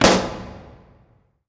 Balloon-Burst-02-edit
balloon burst pop sound effect free sound royalty free Voices